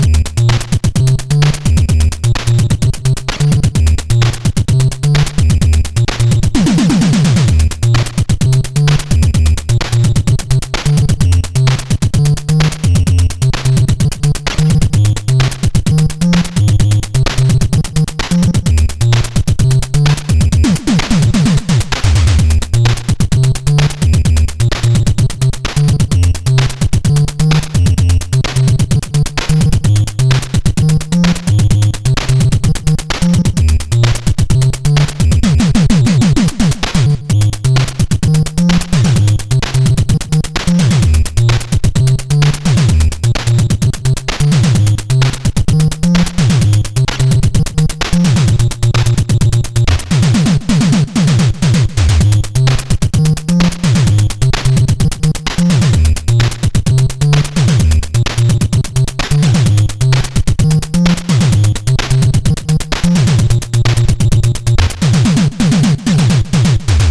With the square wave removed.